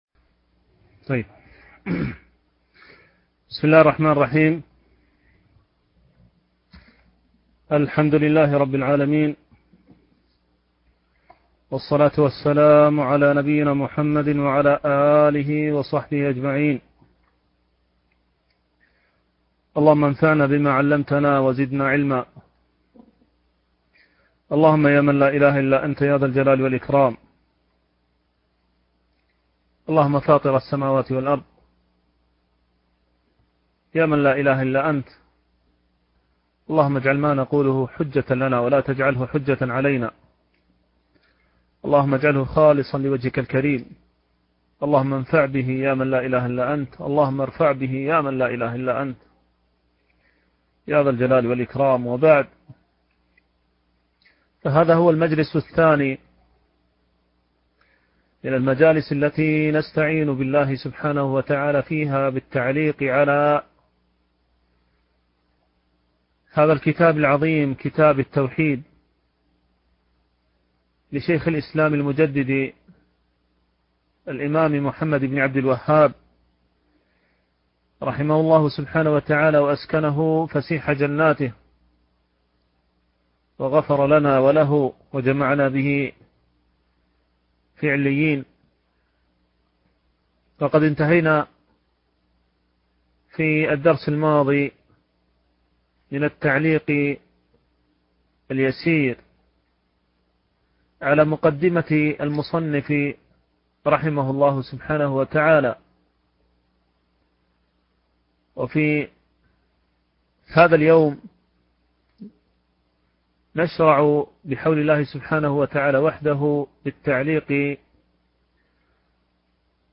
شرح كتاب التوحيد - الدرس الثالث